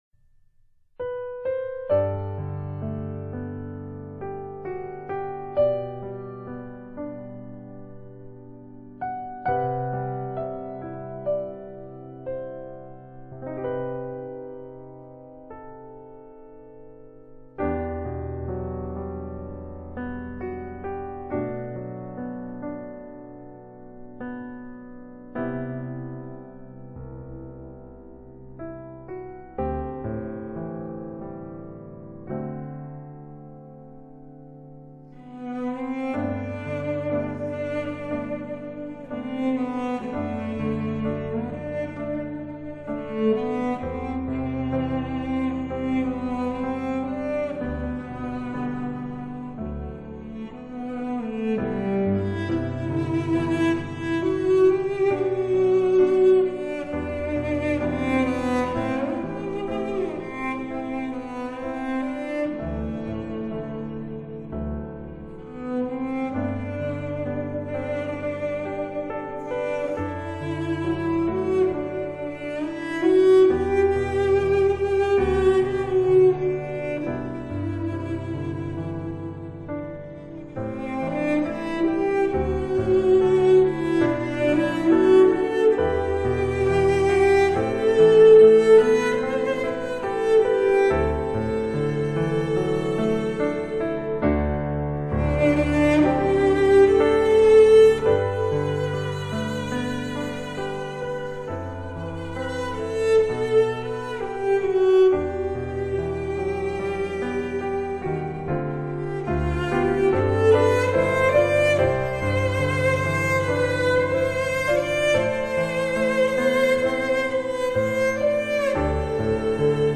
Cello  Could Not Do Without Thy Grace.mp3